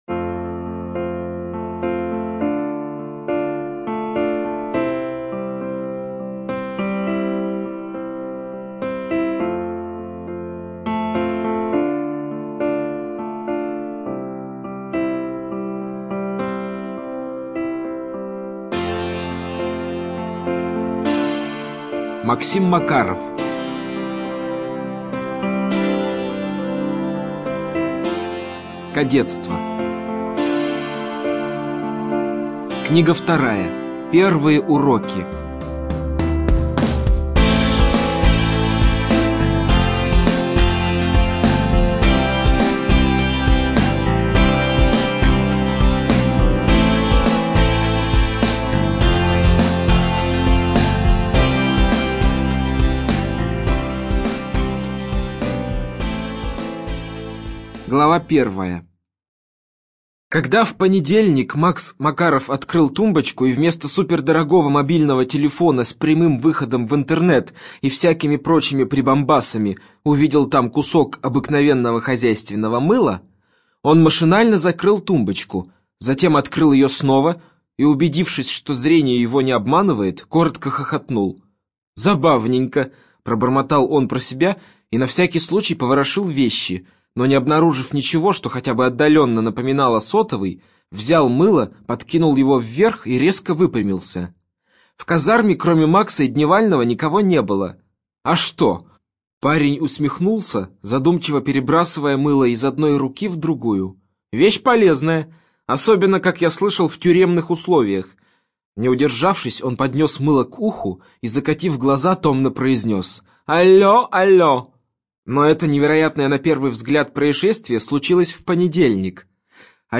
Студия звукозаписиАмфора